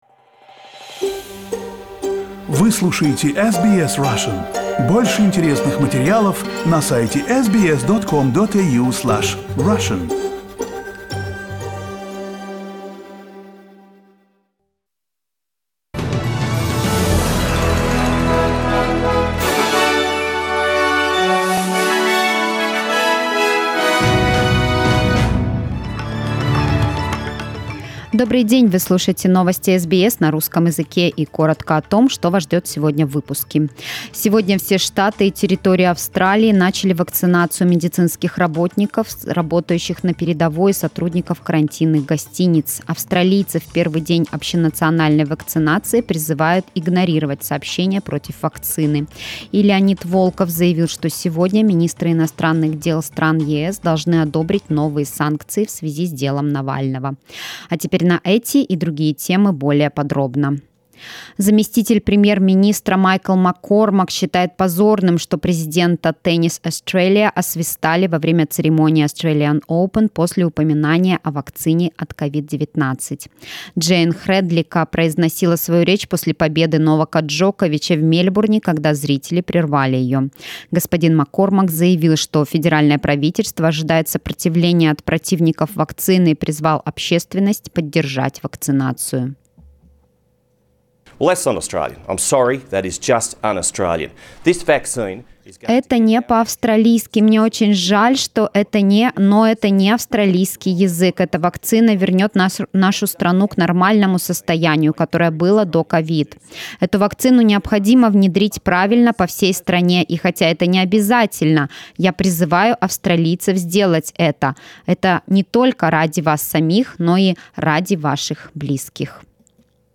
Новостной выпуск за 22 февраля